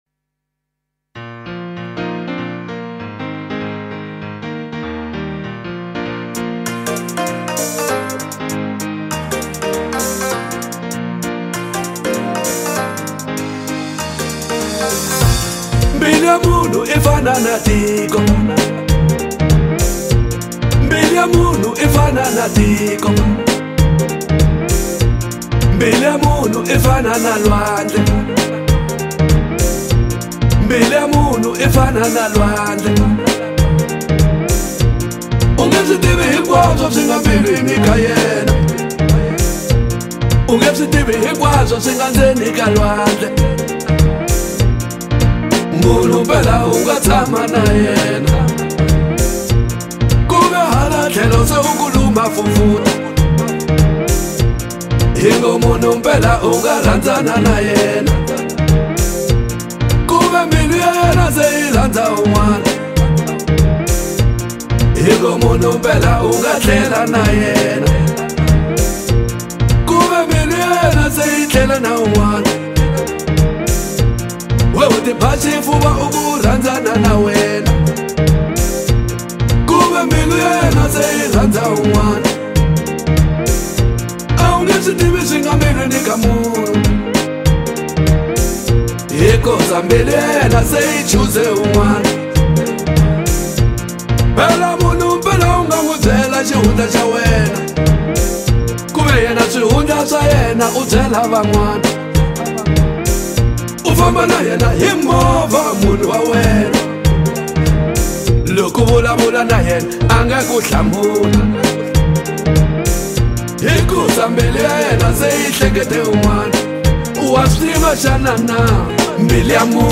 deeply emotional and soulful track
powerful vocals and heartfelt lyrics
If you appreciate powerful,soul stirring music